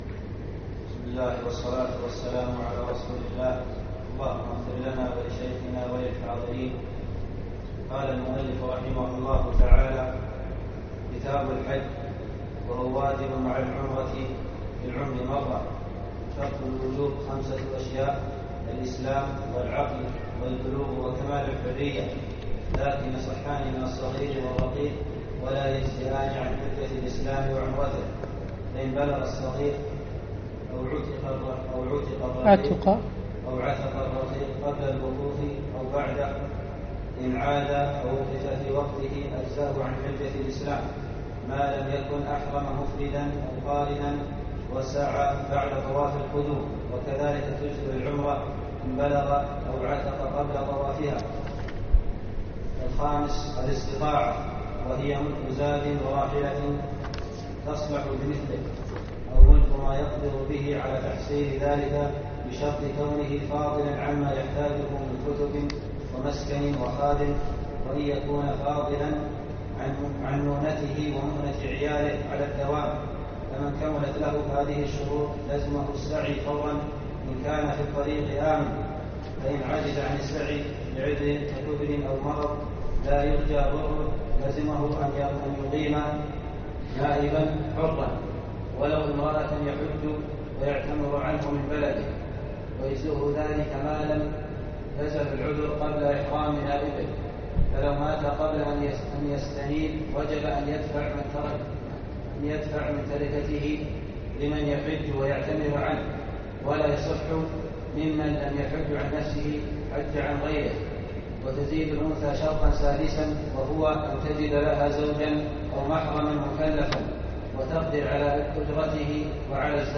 يوم الأحد 22 ذو القعدة الموافق 6 9 2015 بمسجد سالم العلي الفحيحيل
الدرس الأول